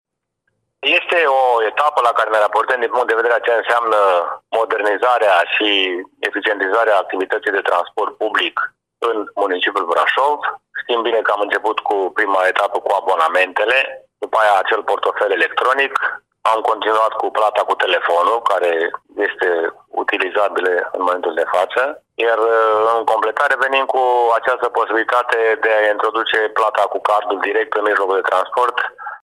Primarul Braşovului, George Scripcaru: